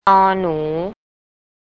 Pronunciation
นอ-หน
nor nhoo